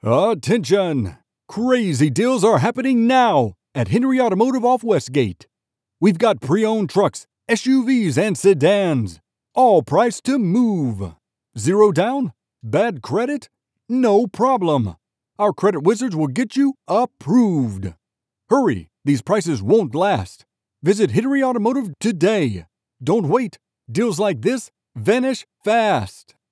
male voiceover artist with a rich, deep, and exceptionally smooth vocal tone.
Hard Sell
General American, Southern